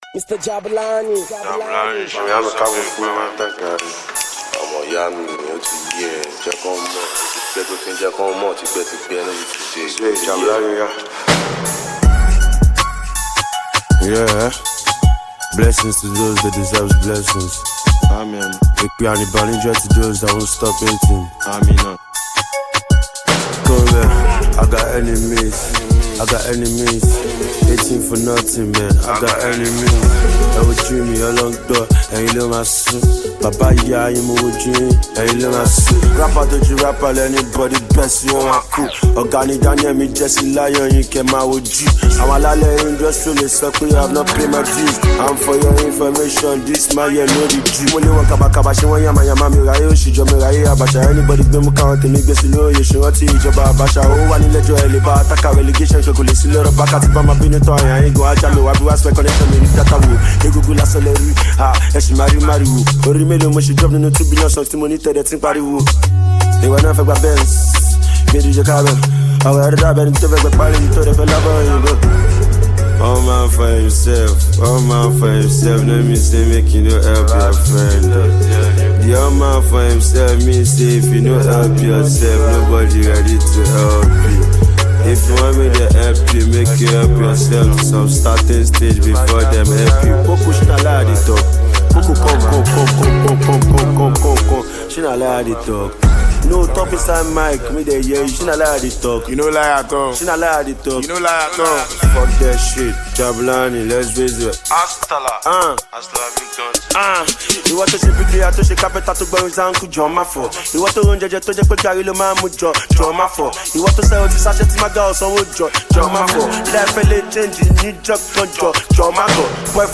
rap single
sublime Hip-Hop joint
with a hard instrumental